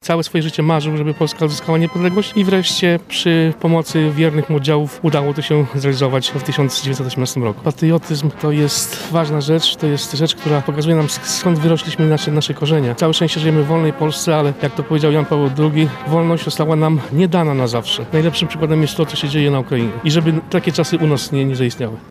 Uroczystość z okazji dnia imienin marszałka Józefa Piłsudskiego odbyła się dziś (16.03) na placu Litewskim w Lublinie.